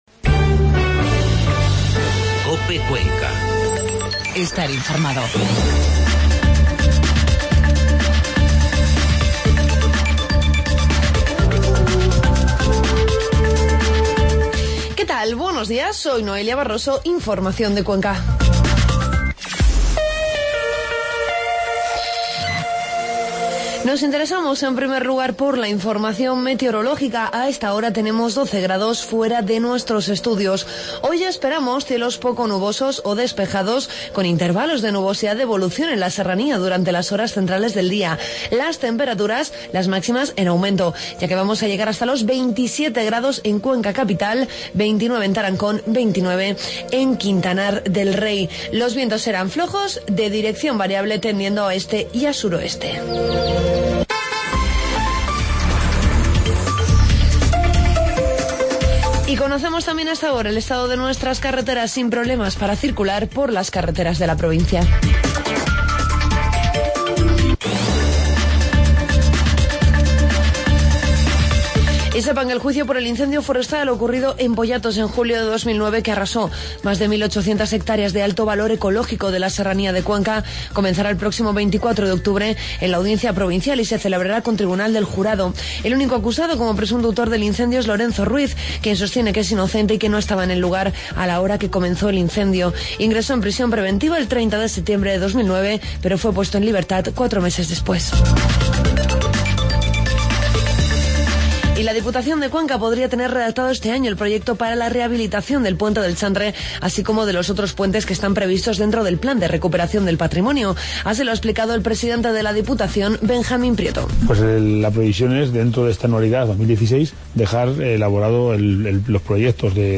Informativo matinal COPE Cuenca